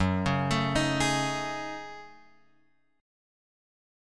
F# Minor 6th Add 9th